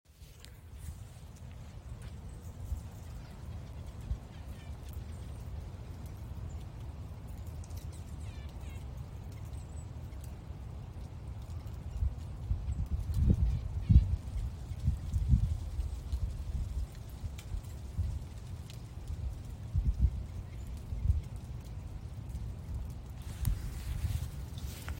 Thumbnails - User: 130 - Red-breasted Nuthatch ( Sitta canadensis ) Quick Category filter...